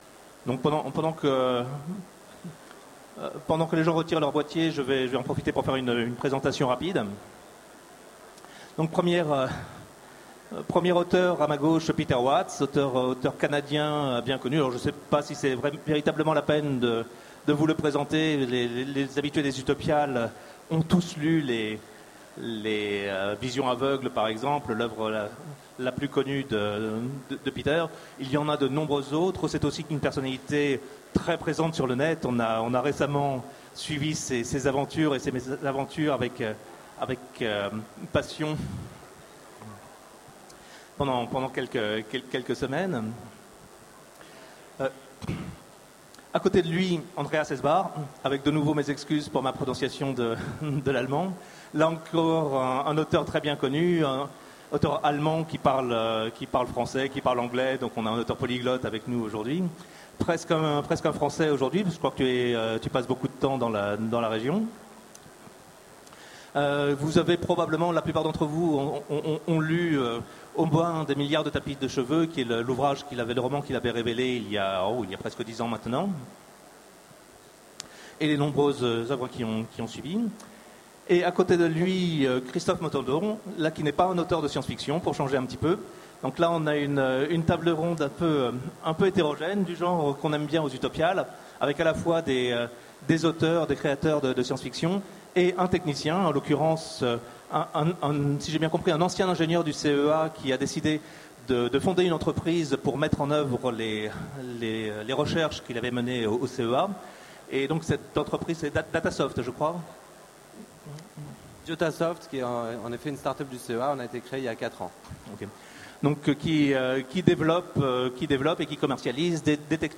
Utopiales 13 : Conférence La vision du futur